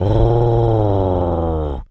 wolf_growl_02.wav